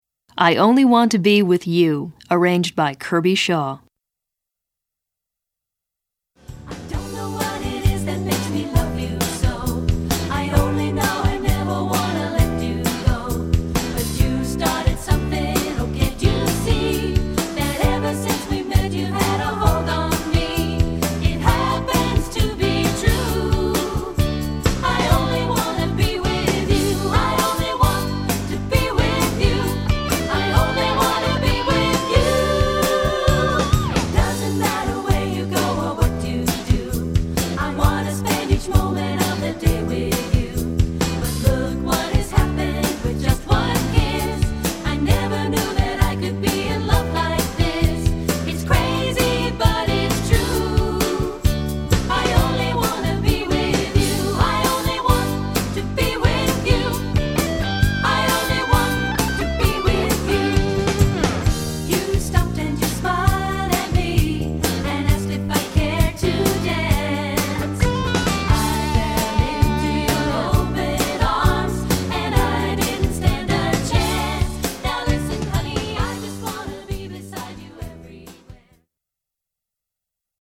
Choeur (Unisson), Voix Hautes et Voix Egales